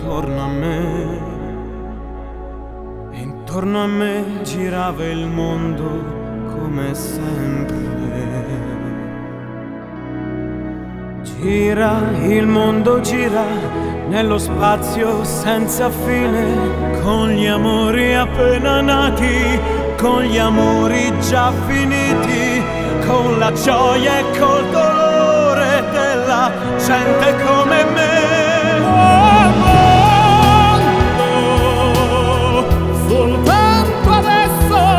Жанр: Поп музыка / Классика